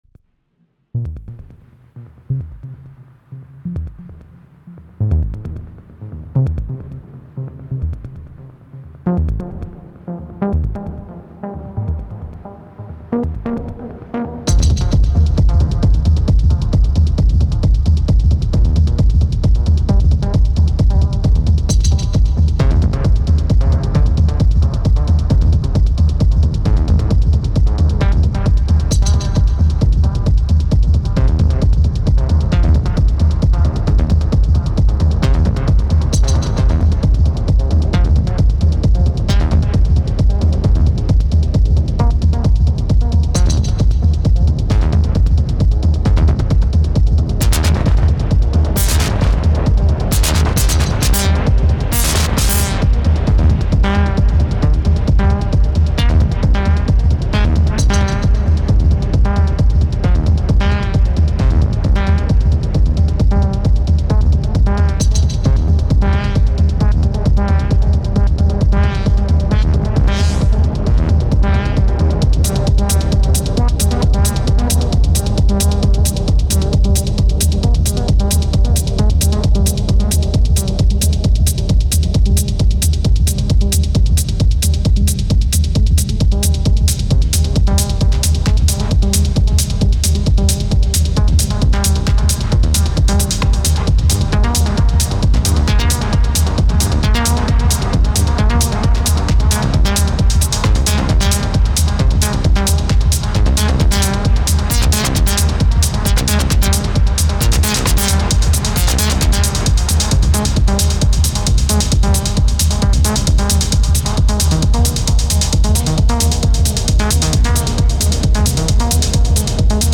The improvised techno thread
improvised ~hour ableton session resulted in this recording, MDD Snake > Analog and two drum kits.